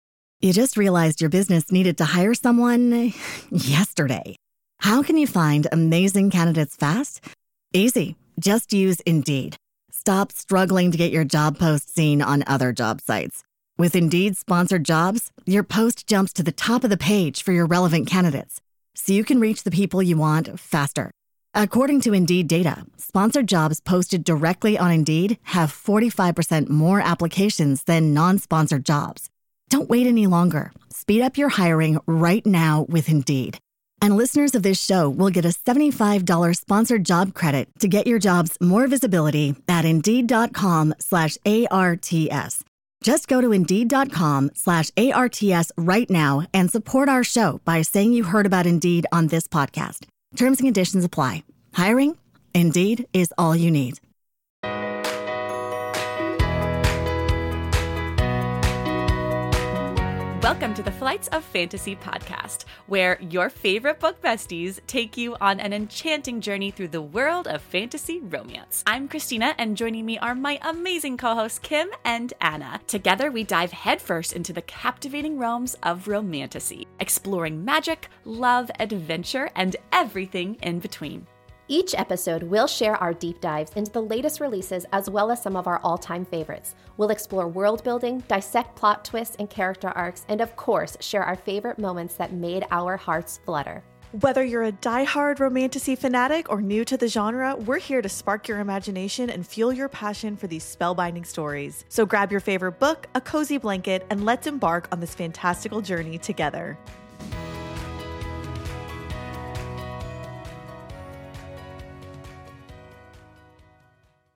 Just a few cauldron blessed and night court obsessed, fire breathing bitch queen besties talking all things fantastical.